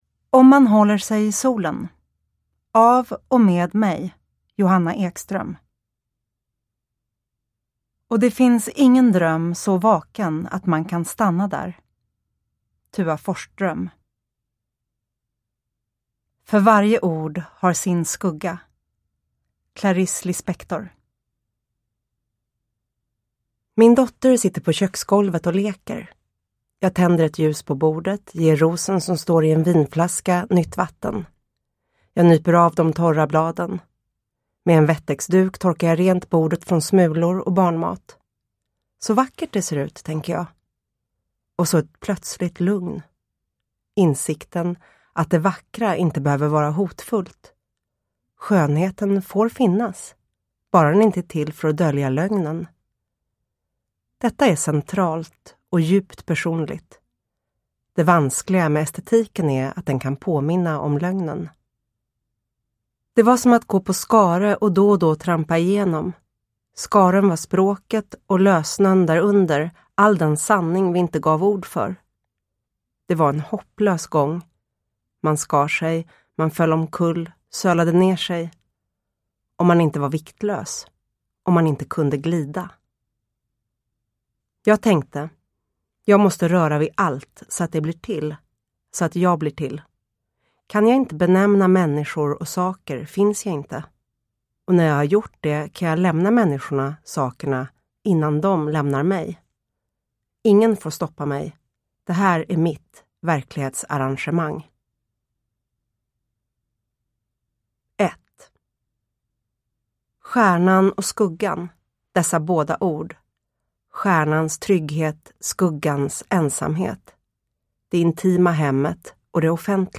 Om man håller sig i solen – Ljudbok – Laddas ner